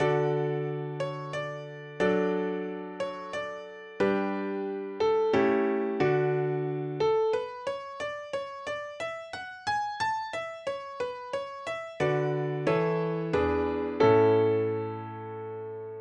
MonophonicMod.ogg